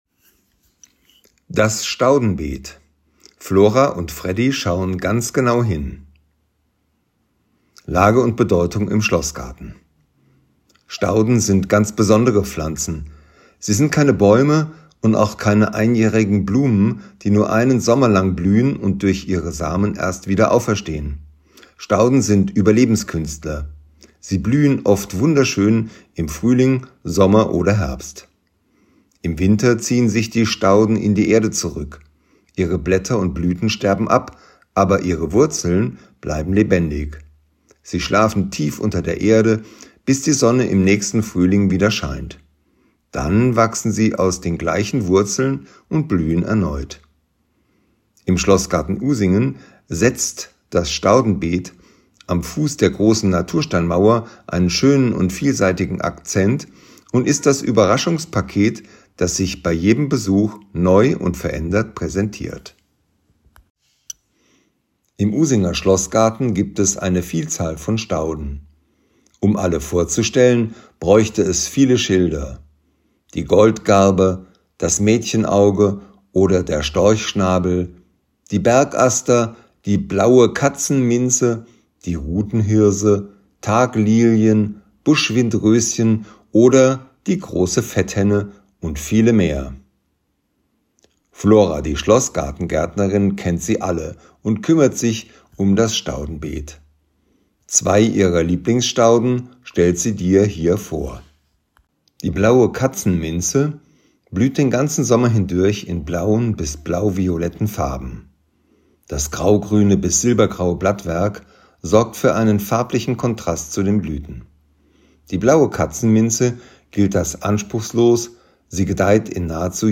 Wer nicht alle Schilder an den Stationen im Schlossgarten lesen möchte oder kann, kann sie sich hier einfach vorlesen lassen.